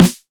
• Low Acoustic Snare F Key 269.wav
Royality free snare sound tuned to the F note. Loudest frequency: 1220Hz
low-acoustic-snare-f-key-269-rl3.wav